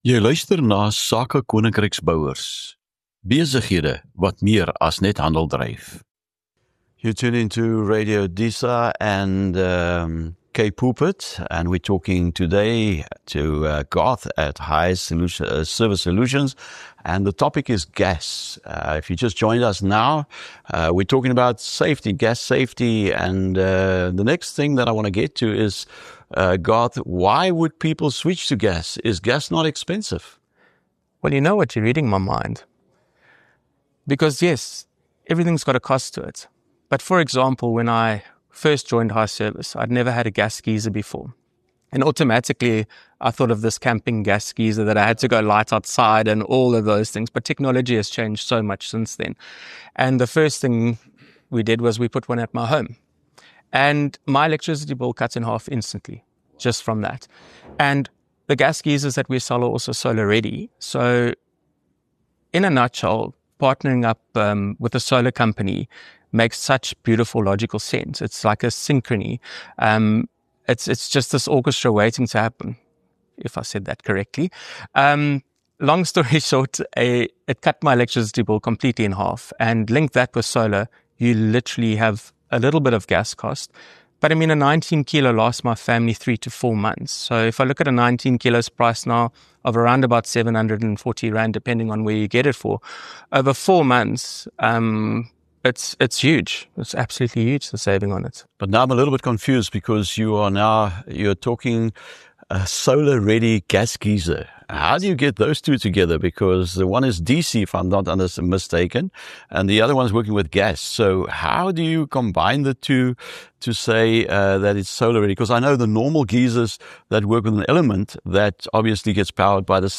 In hierdie vervolg op ons gesprek met Hi Service Gas Solution fokus ons op die voordele van gas vir huishoudings en hoe dit geïntegreer kan word met sonsisteem-gedrewe installations. Ons ondersoek die kostebesparings, hoe verskillende gasbottelgroottes werk vir geisers en stoofinstallasies, en die belangrikheid van professionele, gesertifiseerde installasies. Die episode beklemtoon die maatskappy se verbintenis tot kliëntetevredenheid, integriteit, en die ekstra myl wat hulle loop om seker te maak dat elke kliënt veilig en tevrede is. ŉ Inspirerende gesprek oor familie-gebaseerde besigheid en doeltreffende gasoplossings vir moderne huise.